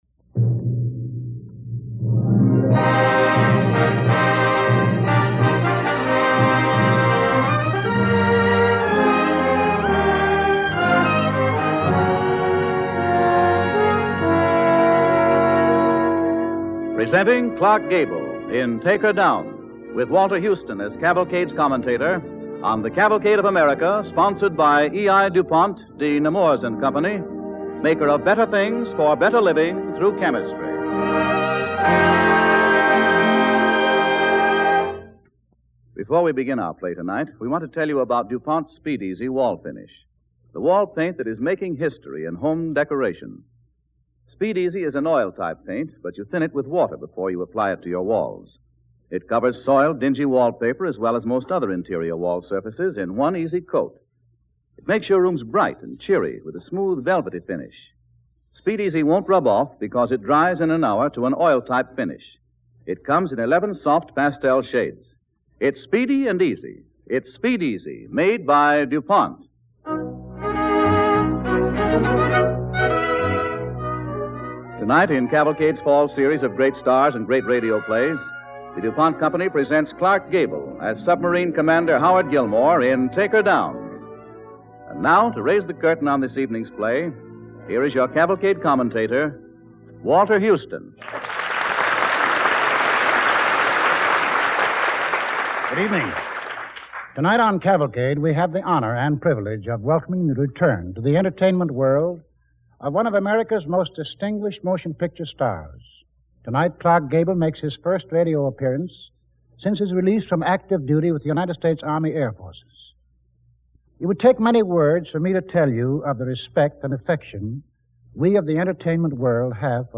starring Clark Gable with host Walter Houston